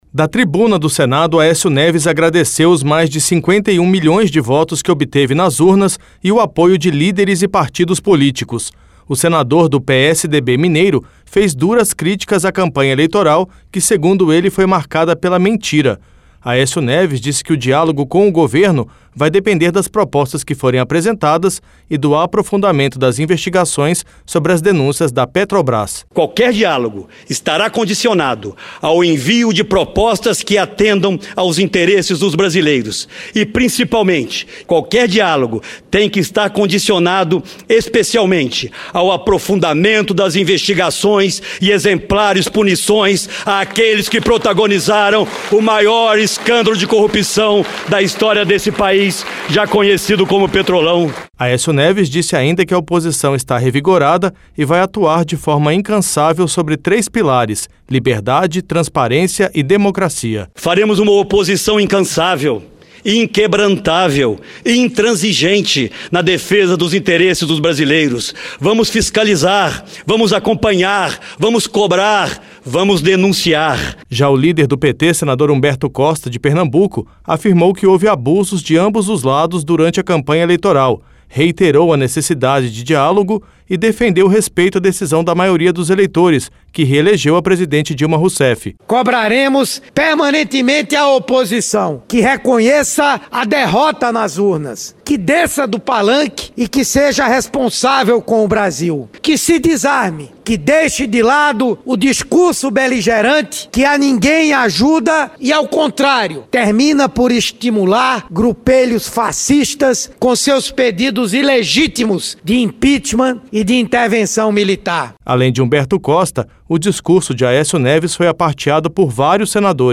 LOC: O SENADOR AÉCIO NEVES, DO PSDB DE MINAS GERAIS, DISSE QUE O DIÁLOGO COM O GOVERNO VAI DEPENDER DO ENVIO DE PROPOSTAS QUE INTERESSEM À POPULAÇÃO E DO APROFUNDAMENTO DAS INVESTIGAÇÕES SOBRE AS DENÚNCIAS DA PETROBRAS. LOC: O CANDIDATO DERROTADO DO PSDB À PRESIDÊNCIA DA REPÚBLICA DISCURSOU DA TRIBUNA DO SENADO PELA PRIMEIRA VEZ APÓS AS ELEIÇÕES DE OUTUBRO.